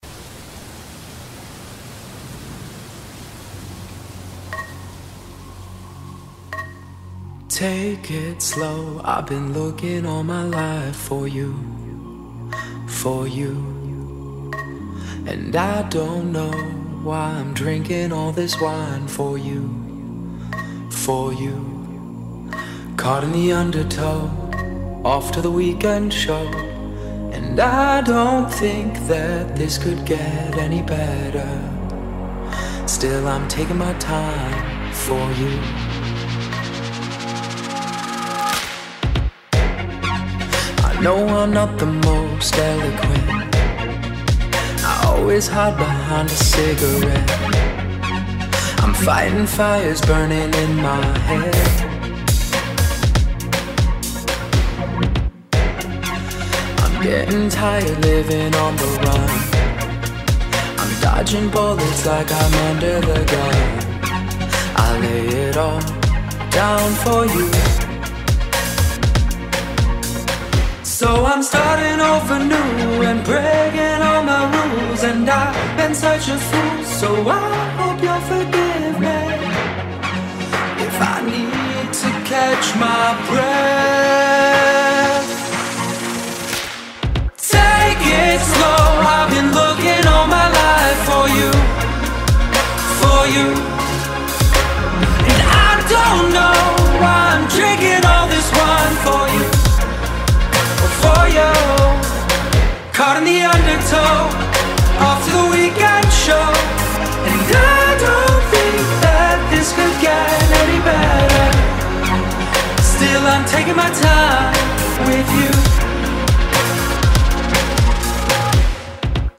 BPM120
Audio QualityMusic Cut